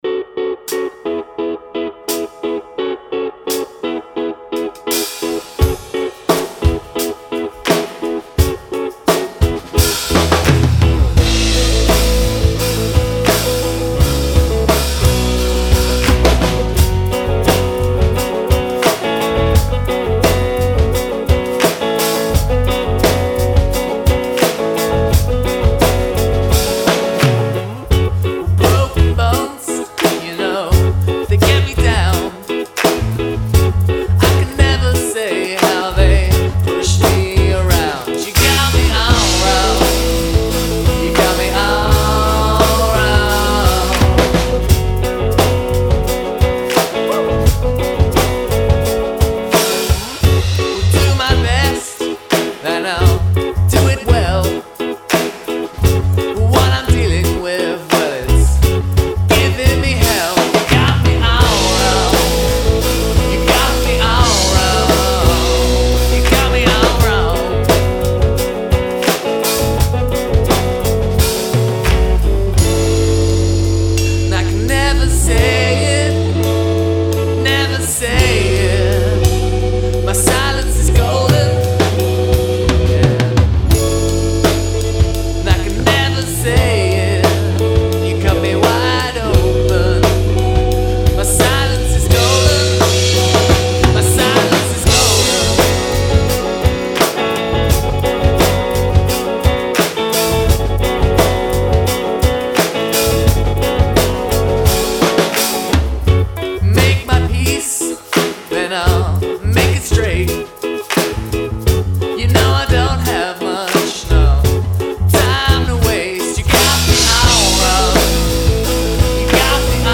The skinny: Loudness from the North.